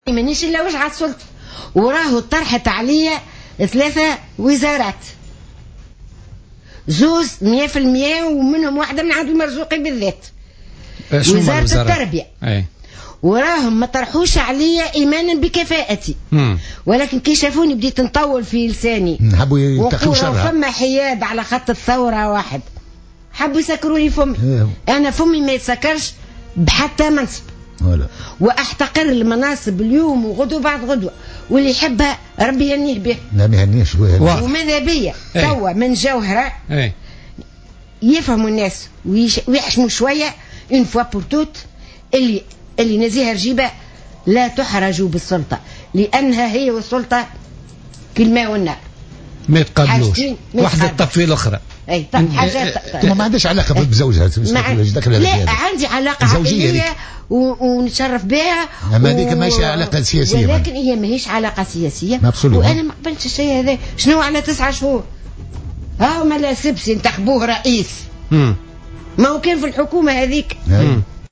قالت الناشطة الحقوقية نزيهة رجيبة "أم زياد" ضيفة برنامج "بوليتيكا" اليوم الثلاثاء إنها رفضت عروضا لتقلّد مناصب وزارية بينها عرض لتولي وزارة التربية من رئيس الجمهورية السابق المنصف المرزوقي.